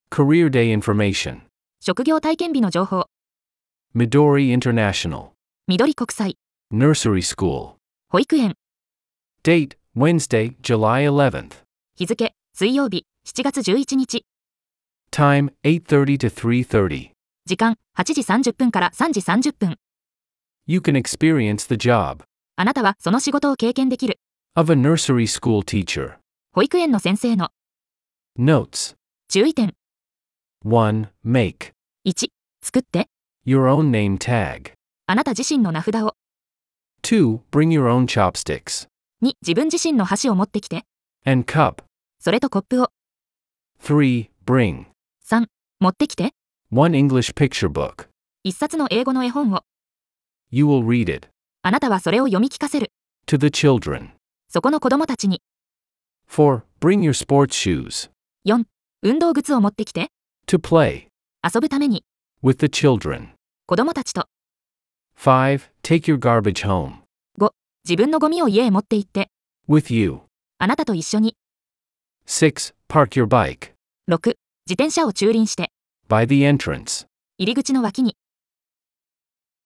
♪ 習得用の音声(英⇒日を区切りごと)：